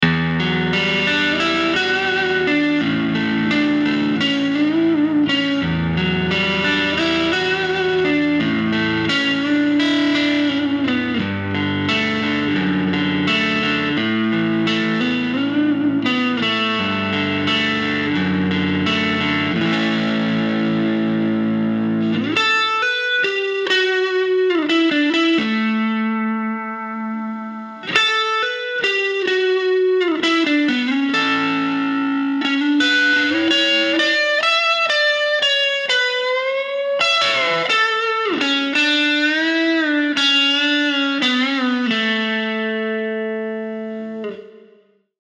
Vintage output Telecaster rhythm tone but with alnico 2 rod magnets for a sweeter, smoother treble response.
APTR-1_CRUNCH_SOLO_SM